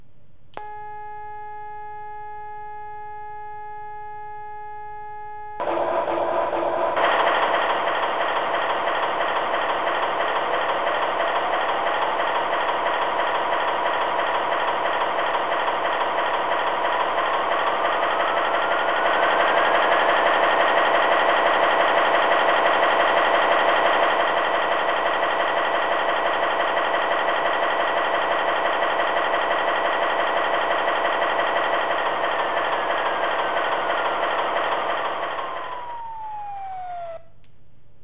The soundcards provide a big variety of heavy diesel locomotive sounds from Britain, North America and Australasia.
EMD 710